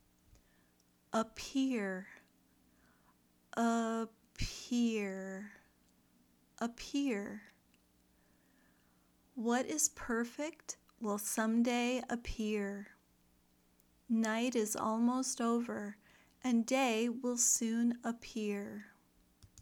ˈpɪr (verb)